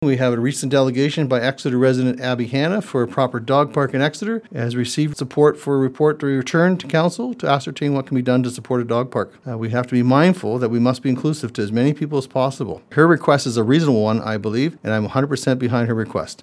As we look to the new year, South Huron Mayor George Finch stopped by the myFM studio and highlighted the community’s accomplishments in 2024, reflecting on a year filled with resilience, collaboration, and growth.